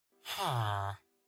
Minecraft Villager Sound
Play Minecraft Villager Sound Sound Button For Your Meme Soundboard!